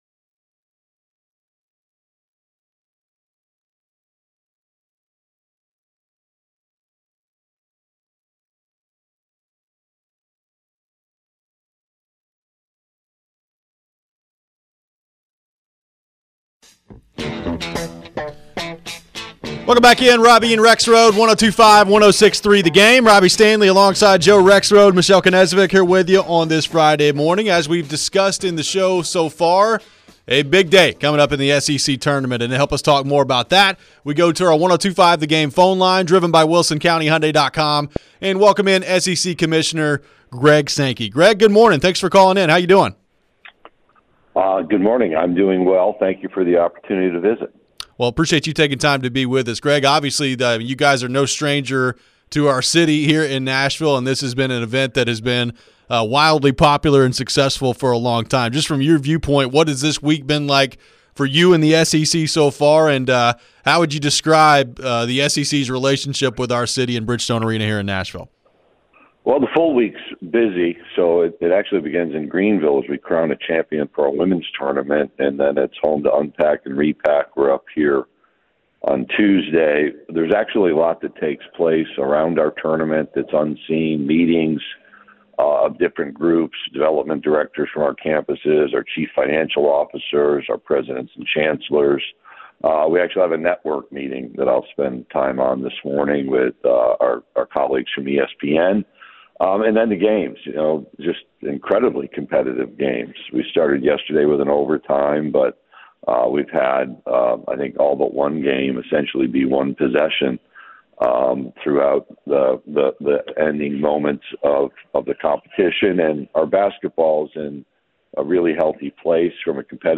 Interview with SEC Commissioner Greg Sankey